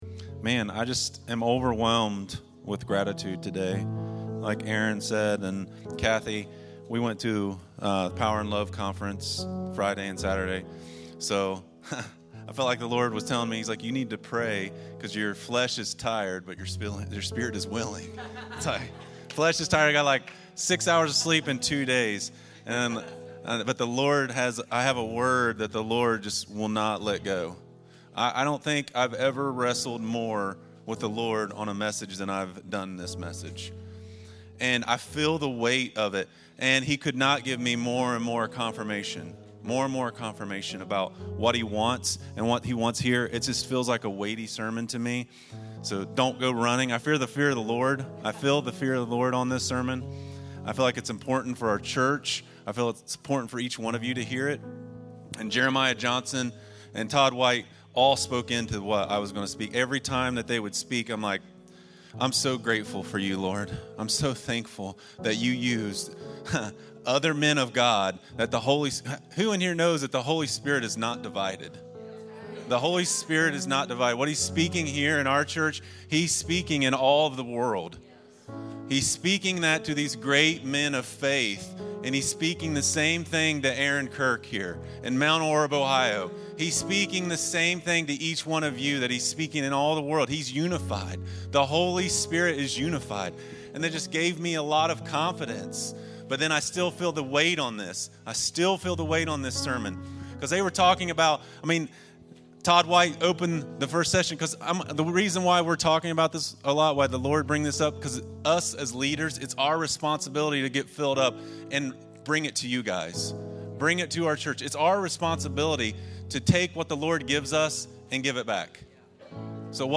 Rooted To Thrive - Rooted To Thrive ~ Free People Church: AUDIO Sermons Podcast